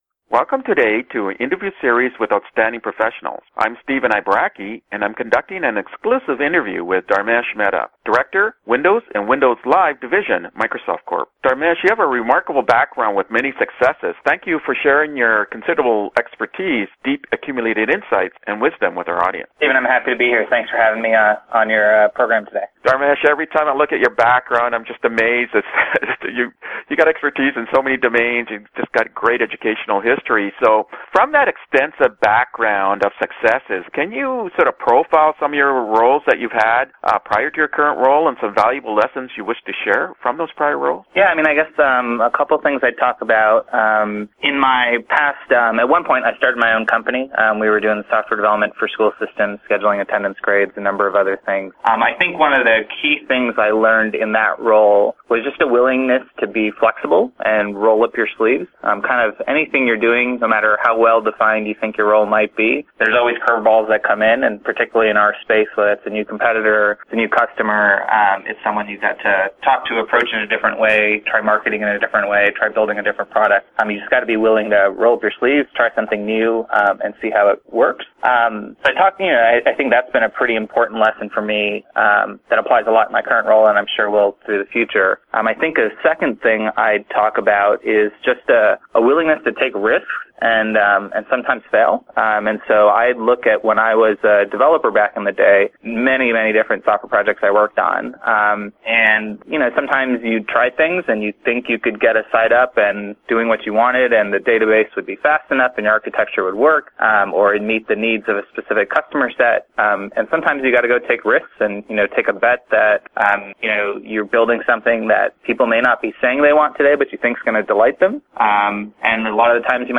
Interview Time Index (MM:SS) and Topic